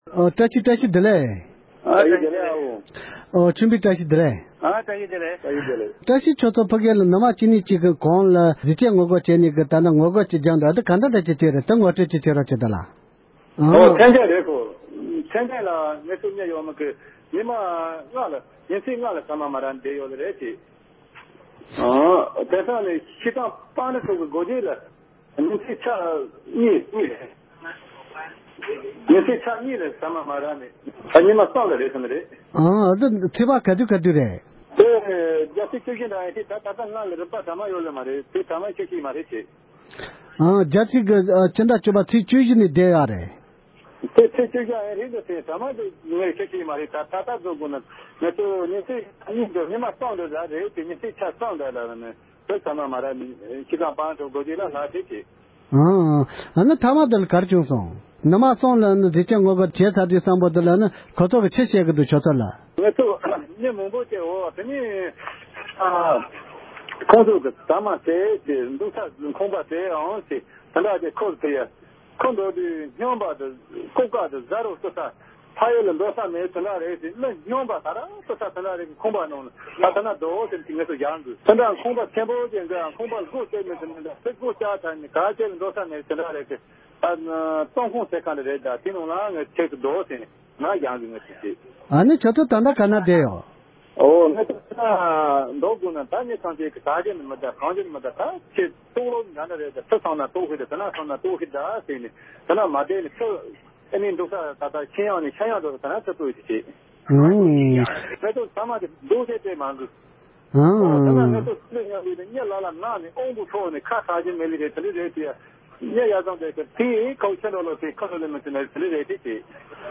འབྲེལ་ཡོད་མི་སྣ་ལ་བཀའ་འདྲི་ཞུས་པ་ཞིག་ལ་གསན་རོགས་གནོངས༎